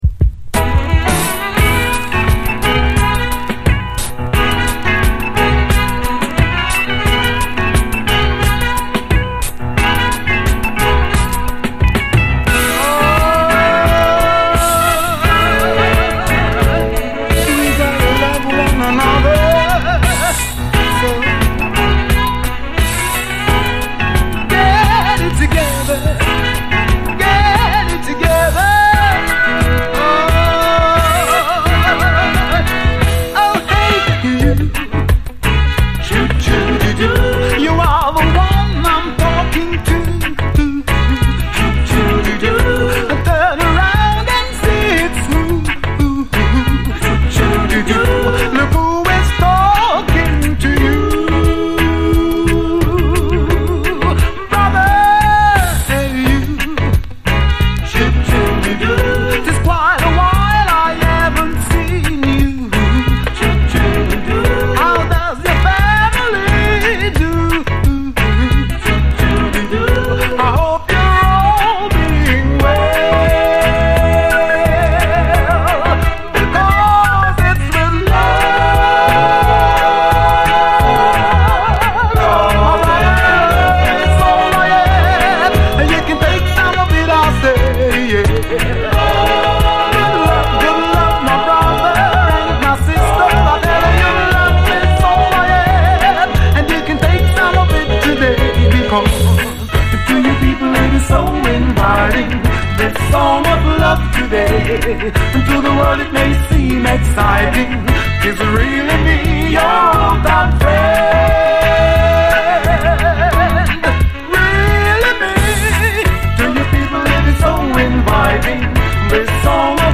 盤見た目少しスレありますが実際は概ね綺麗に聴けます。
MONO